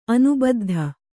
♪ anubaddha